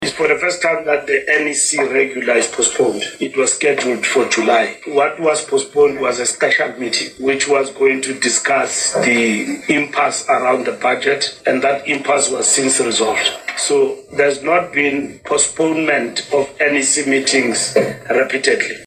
Addressing a media briefing at Luthuli House on Wednesday, 16 July, ANC Secretary-General Fikile Mbalula confirmed that the party has postponed its upcoming National Executive Committee (NEC) meeting. The delay is intended to allow broader political preparations on issues such as the Government of National Unity (GNU) and deteriorating alliance relations, notably with the South African Communist Party (SACP).